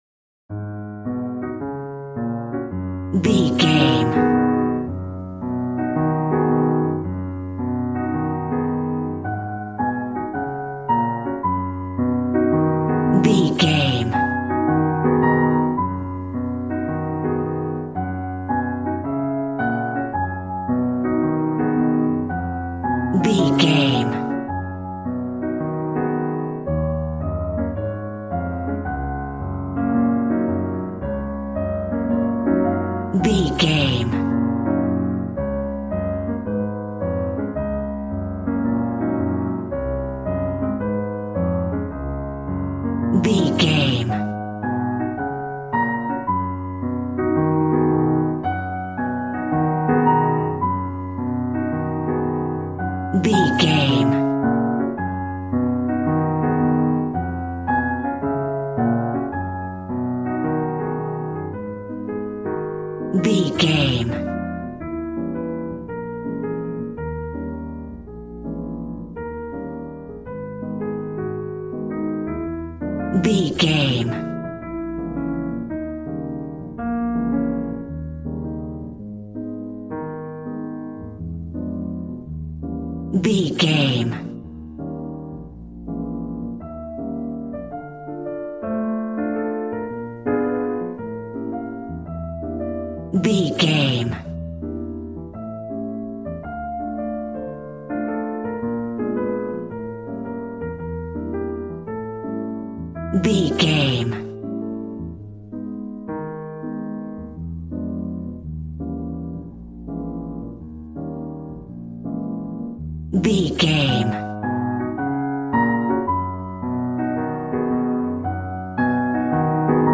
Smooth jazz piano mixed with jazz bass and cool jazz drums.,
Aeolian/Minor
E♭
smooth
drums